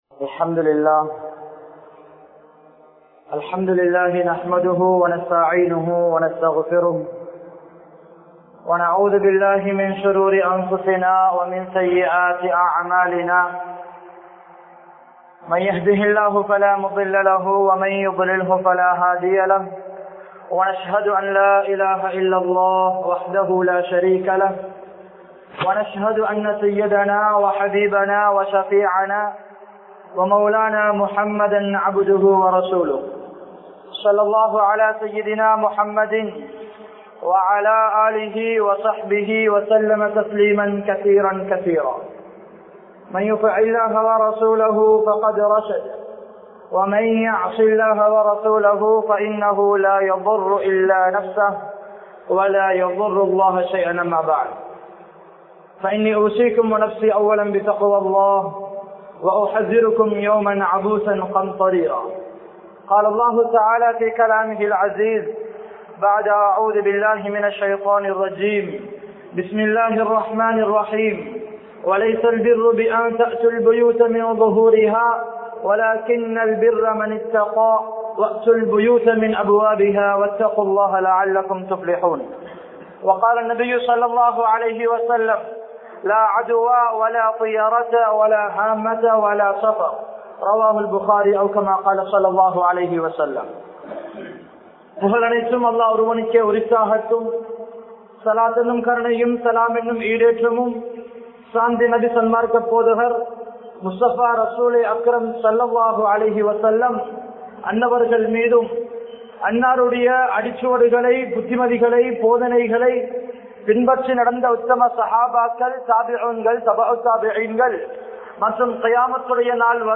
Mooda Nambikkaihal (மூடநம்பிக்கைகள்) | Audio Bayans | All Ceylon Muslim Youth Community | Addalaichenai